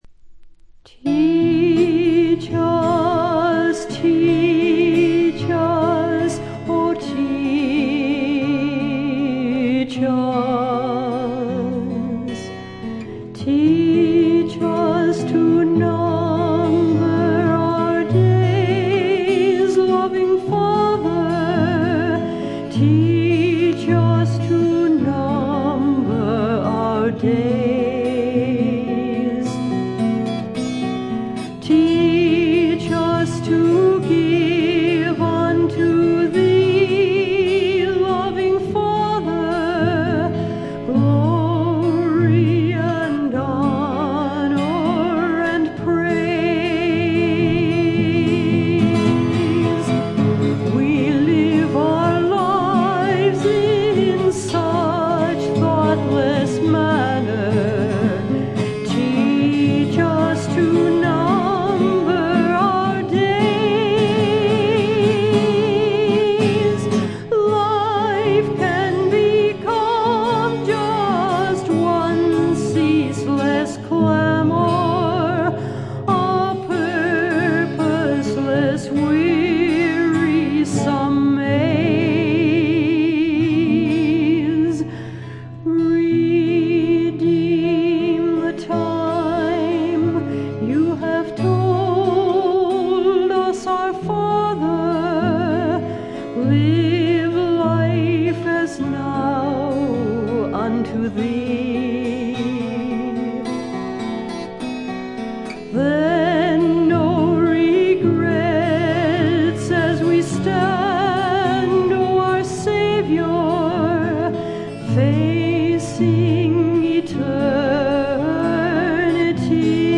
軽いチリプチ程度。
ミネアポリス産クリスチャン・ミュージック／女性シンガーソングライターの佳作。
この時点で一児の母親のようですが、純真無垢な歌声に癒やされます。
試聴曲は現品からの取り込み音源です。
Recorded At - Sound 80 Studios